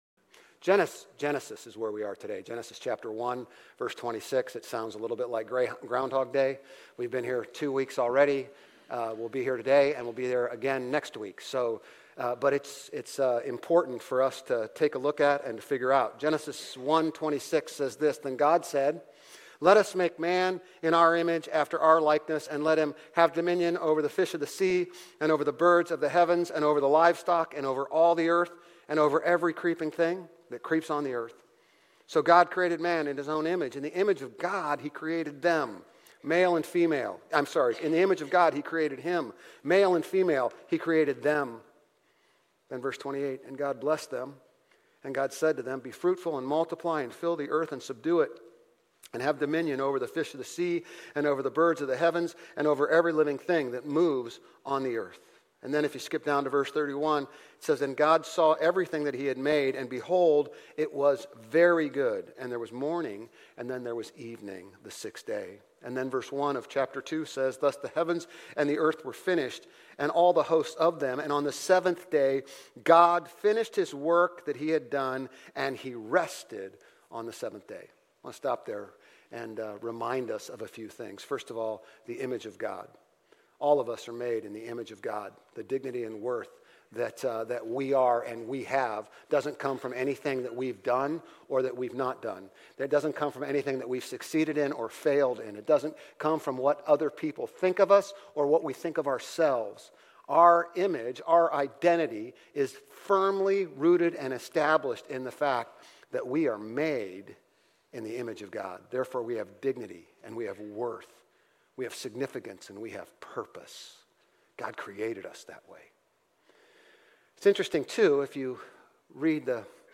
Grace Community Church Old Jacksonville Campus Sermons Genesis 1:26-28, 2:21-25 - Gender & Gender Roles Sep 09 2024 | 00:30:57 1x 00:00 / 00:30:57 Subscribe Share RSS Feed Share Link Embed